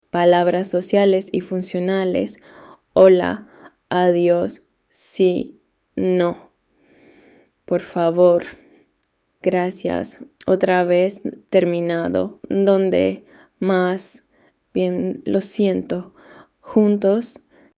Lesson 8